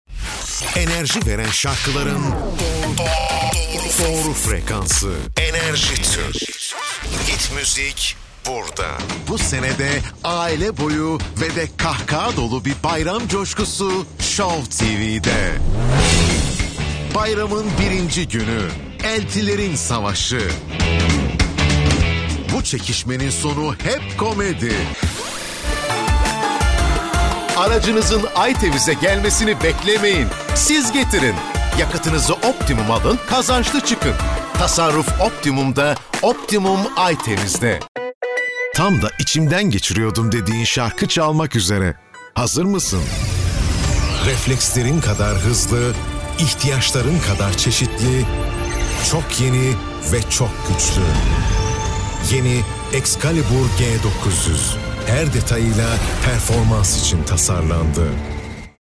Er hat eine reiche, gut artikulierte Baritonstimme.
Vertrauenswürdig
Warm